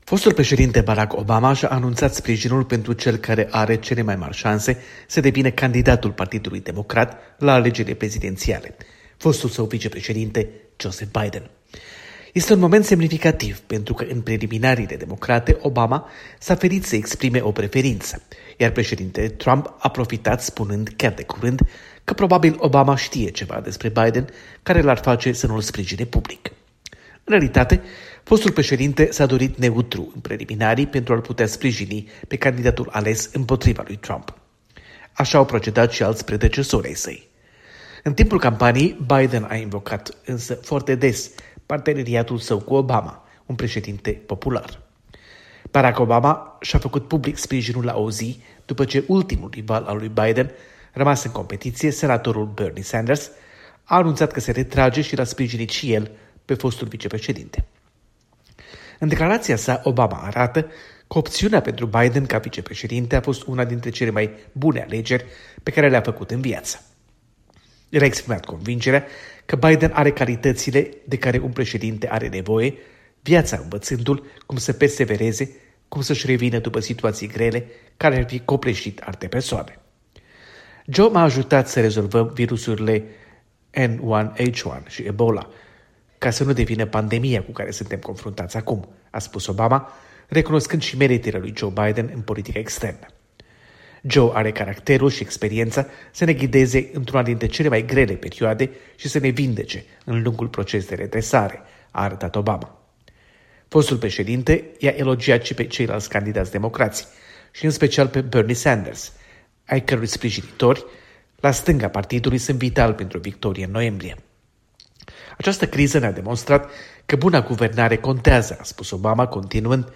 Corspondență de la Washington: fostul președinte Barack Obama